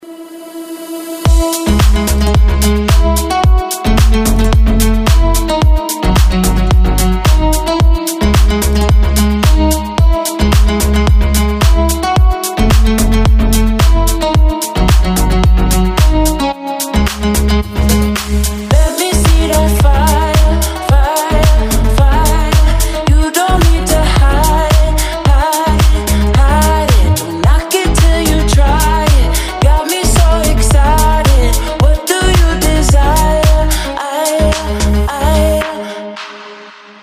• Качество: 128, Stereo
deep house